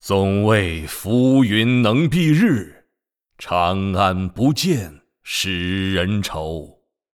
移动语音